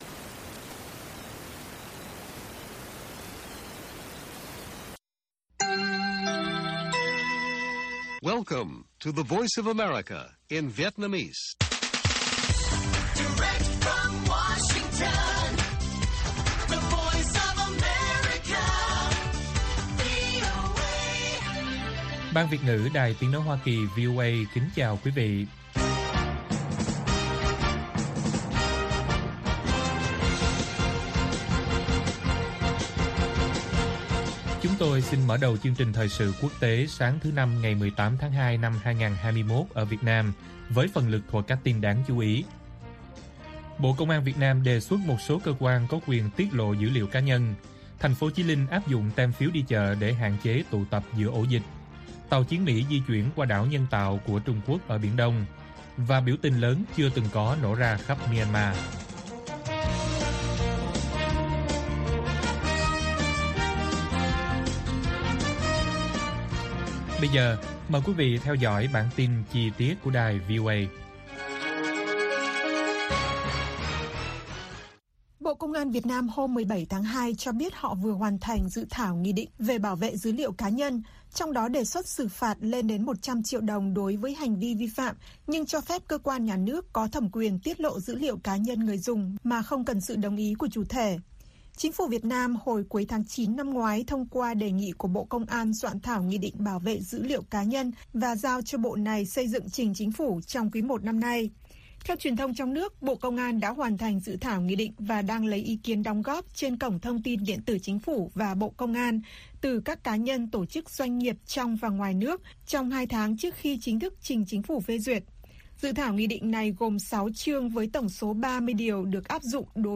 Bản tin VOA ngày 18/2/2021